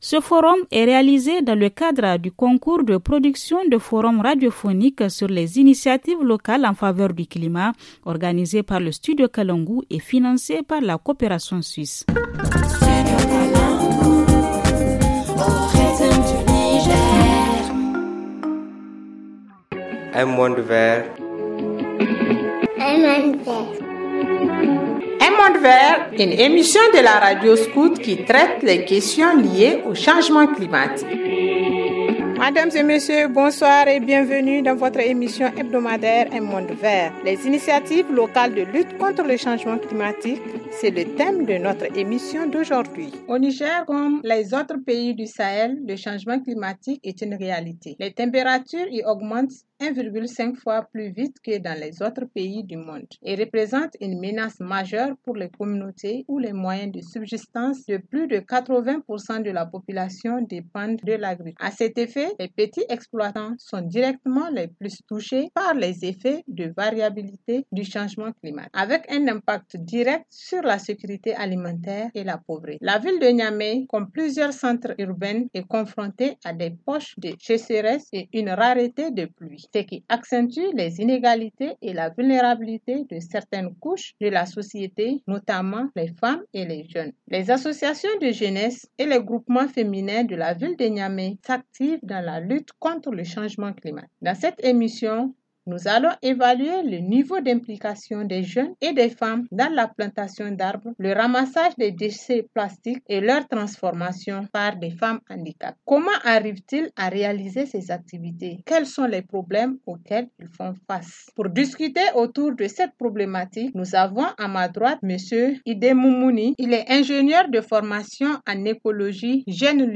ok-FR-FORUM-INITIATIVE-LOCALES-RADIO-SCOUT-0211.mp3